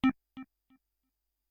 sound_demon.ogg